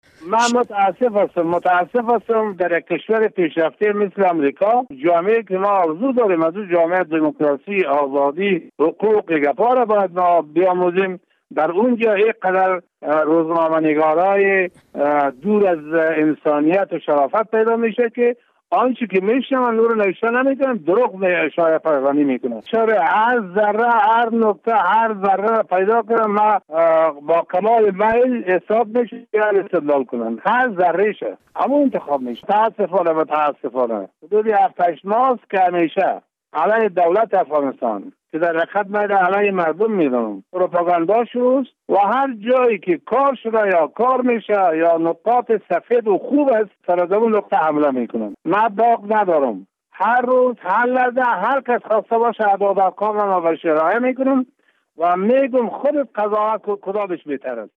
مصاحبه با ابراهیم عادل وزیر معادن افغانستان